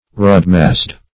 Search Result for " rudmasday" : The Collaborative International Dictionary of English v.0.48: Rudmasday \Rud"mas*day\ (r[=oo]d"m[.a]s*d[=a]`), n. [See Rood , Mass , Day .]